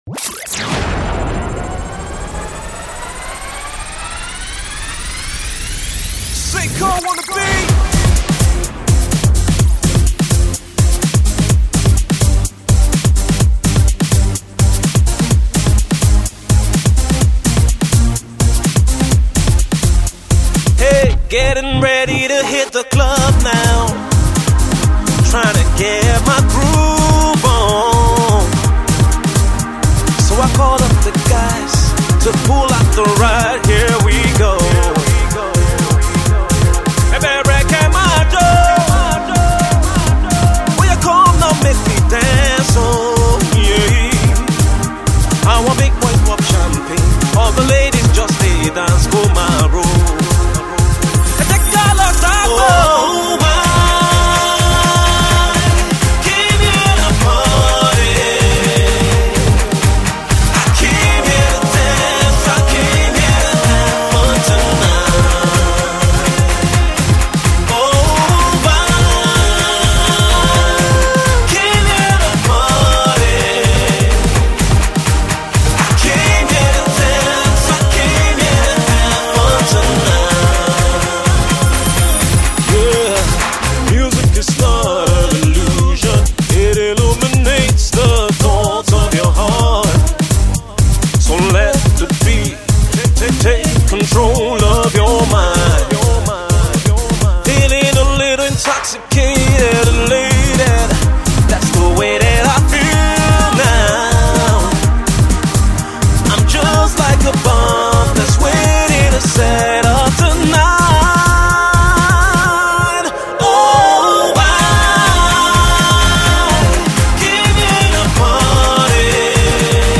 Dance/Pop singles